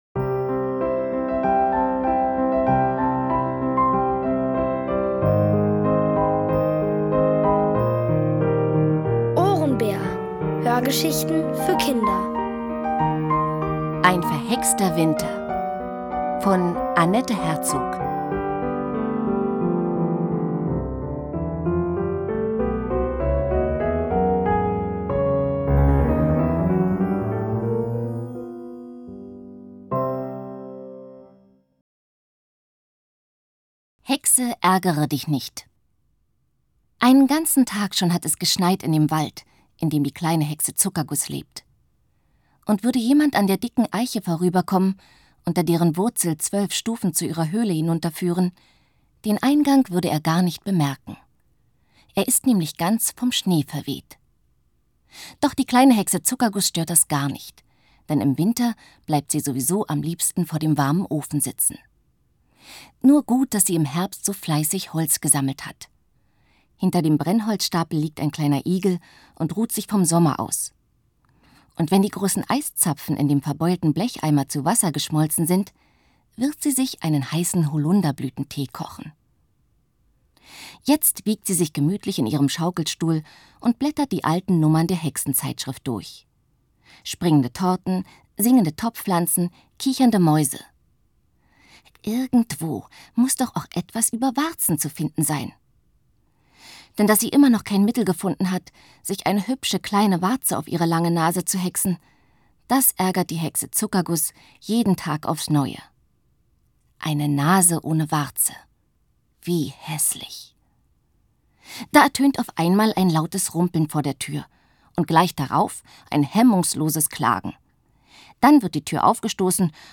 OHRENBÄR – das sind täglich Hörgeschichten für Kinder zwischen 4 und 8 Jahren. Von Autoren extra für die Reihe geschrieben und von bekannten Schauspielern gelesen.